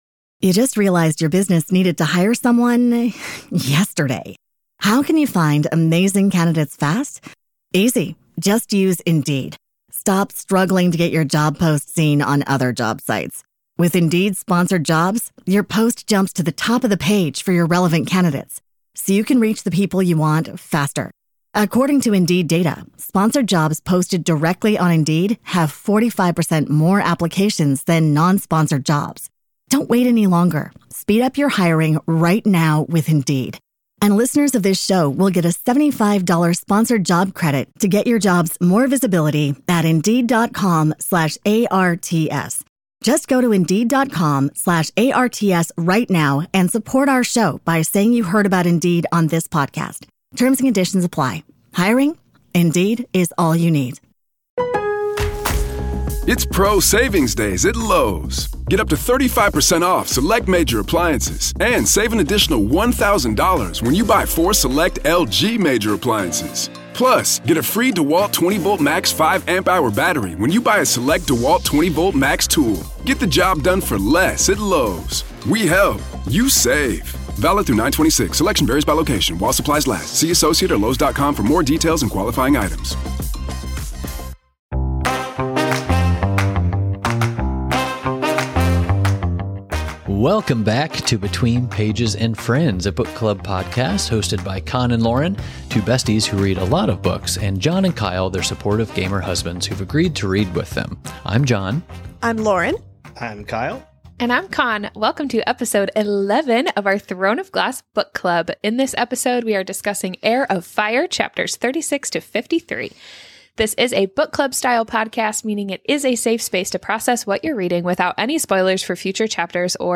You'll never guess why we mention Daytona Beach in this episode 💀 come chat about HEIR OF FIYAAA with us! At the end, you'll notice a change in the video – we had a crazy recording error and had to re-record the end of this episode on a different day.